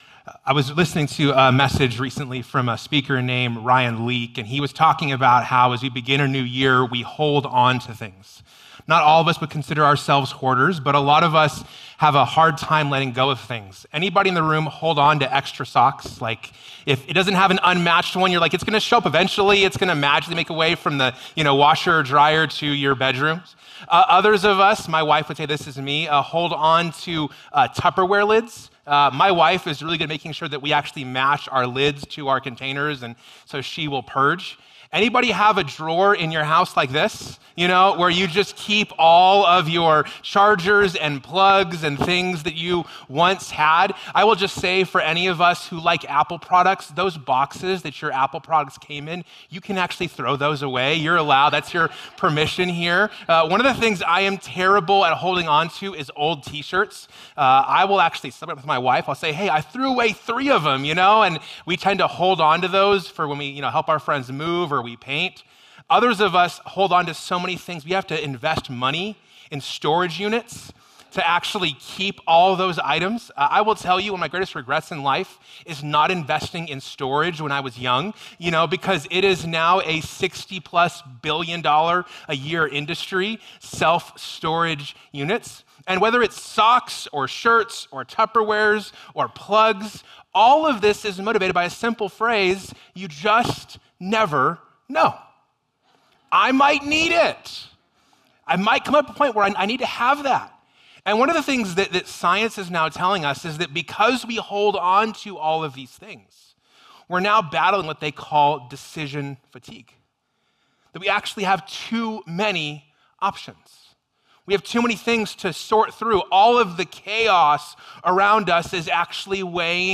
📍 Cornerstone Church, Prescott, AZ